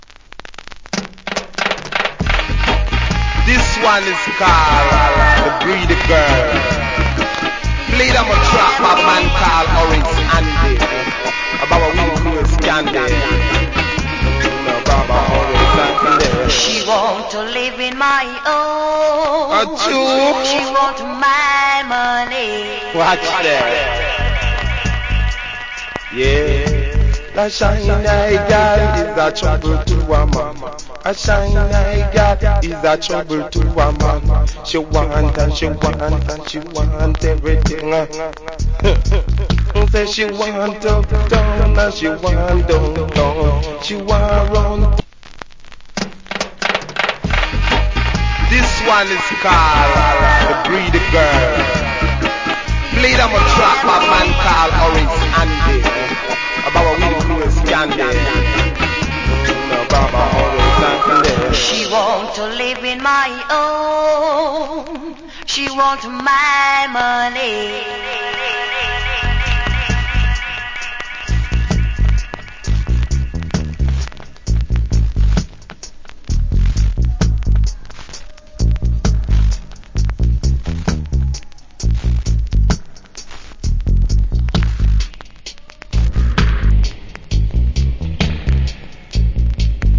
Killer DJ.